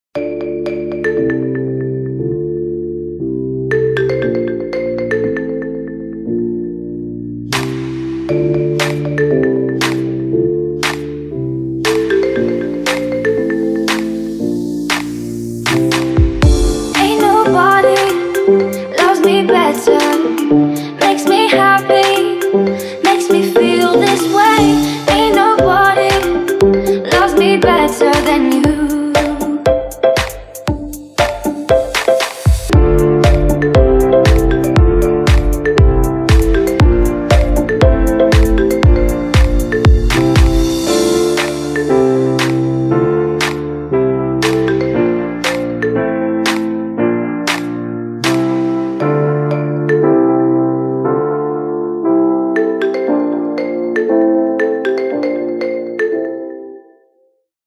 • Качество: 320, Stereo
милые
спокойные